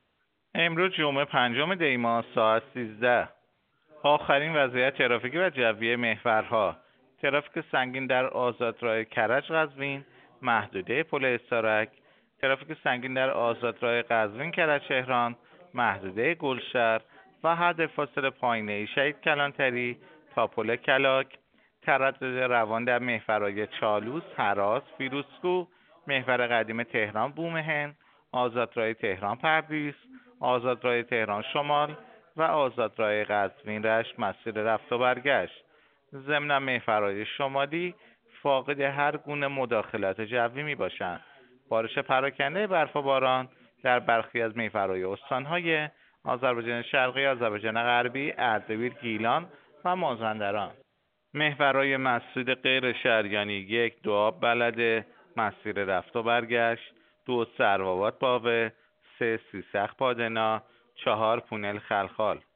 گزارش رادیو اینترنتی از آخرین وضعیت ترافیکی جاده‌ها ساعت ۱۳ پنجم دی؛